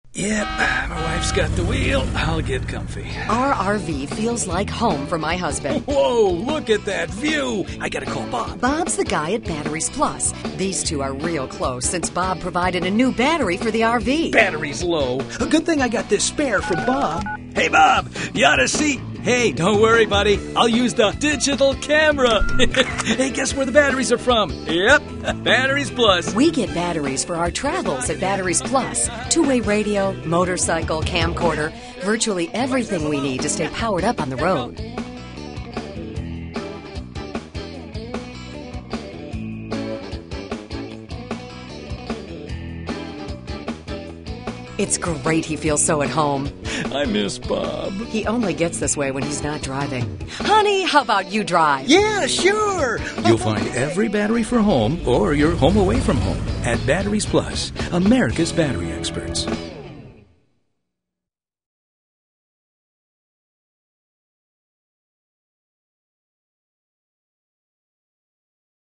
Batteries Plus Radio Commercial